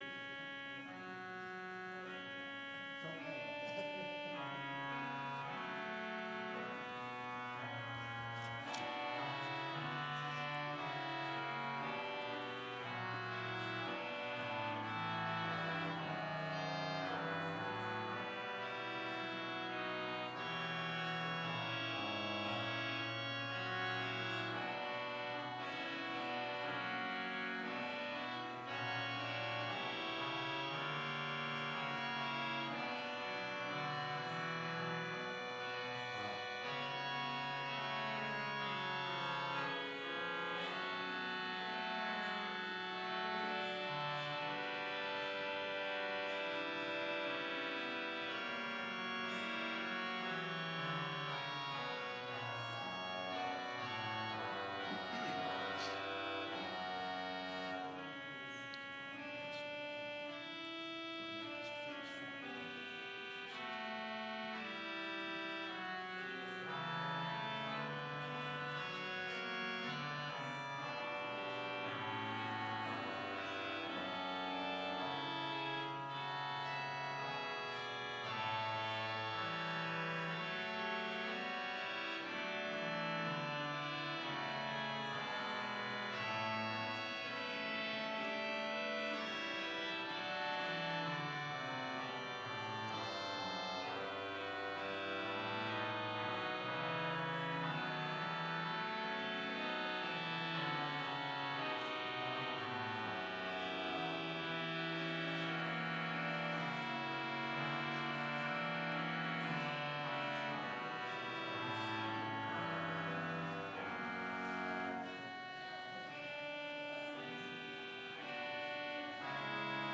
Complete service audio for Chapel - November 19, 2019
Order of Service Prelude Hymn 537 - Day of Wrath, O Day of Mourning